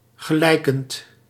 Ääntäminen
IPA: [pa.ʁɛj]